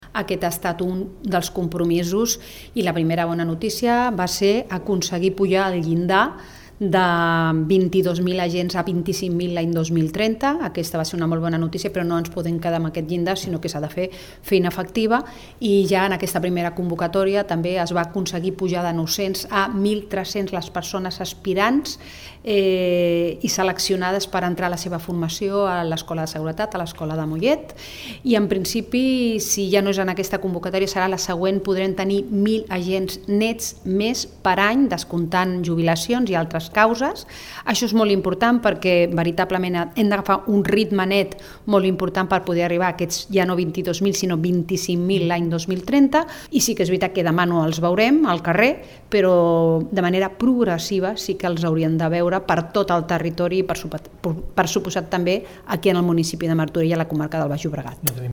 Pilar Díaz, delegada territorial Generalitat a Barcelona